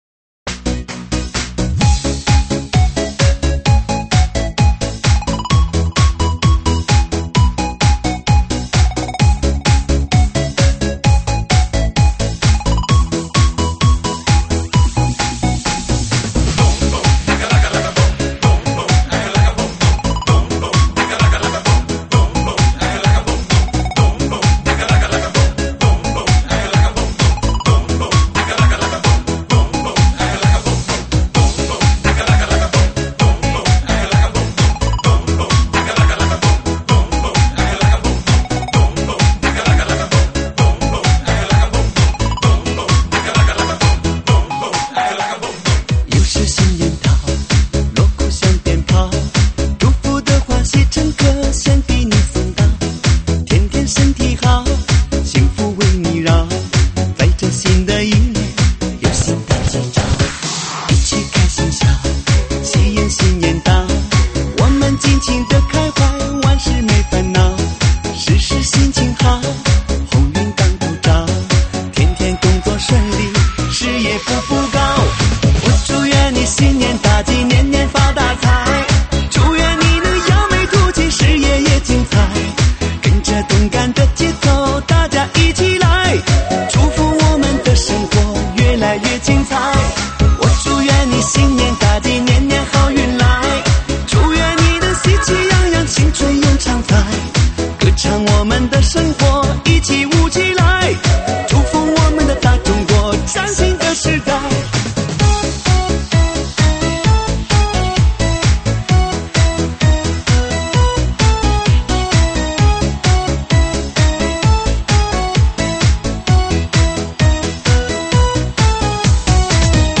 新年喜庆